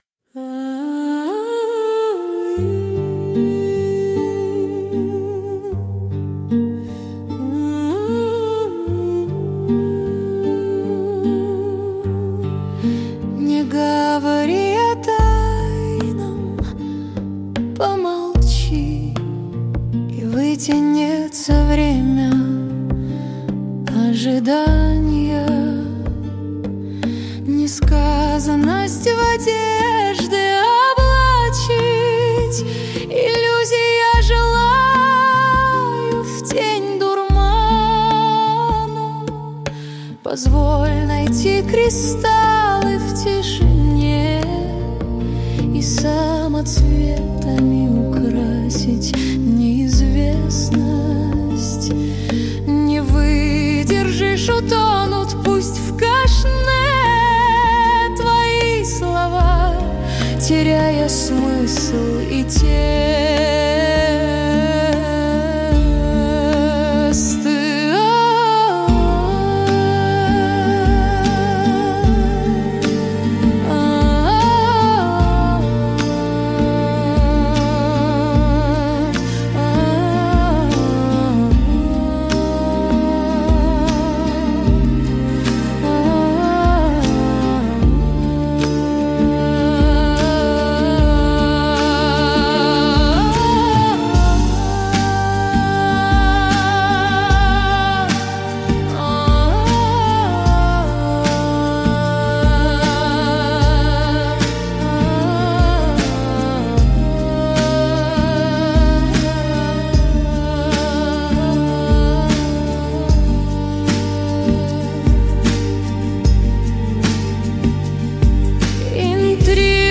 mp3,8947k] Авторская песня